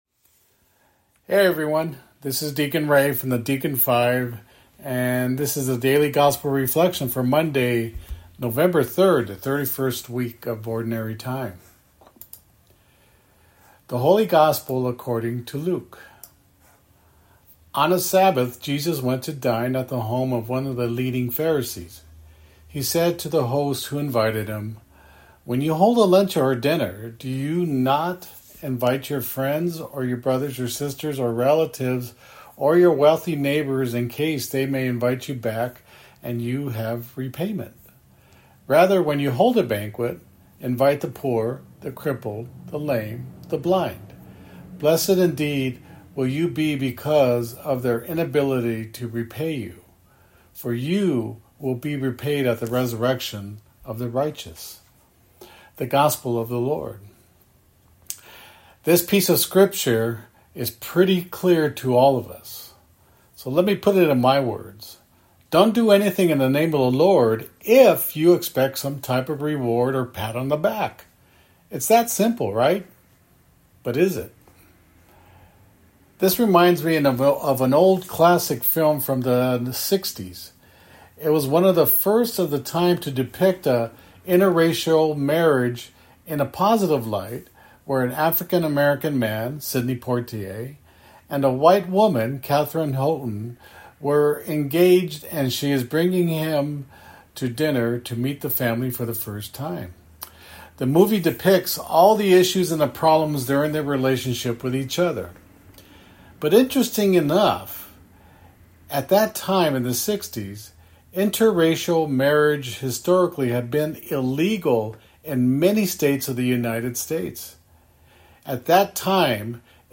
Audio Reflection: